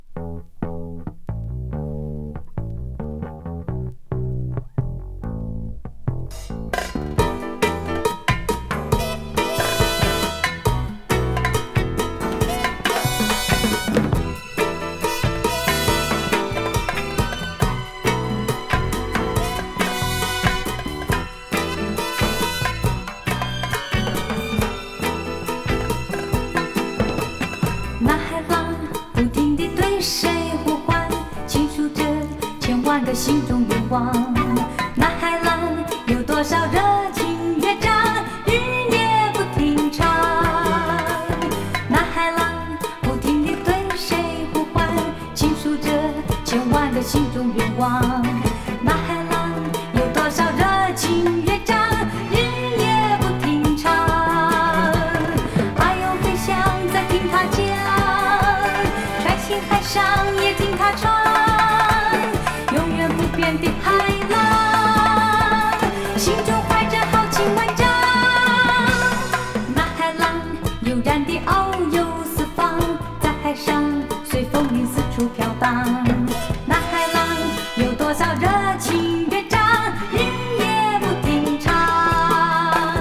イントロのベースからクールな！ラテンチック・ディスコ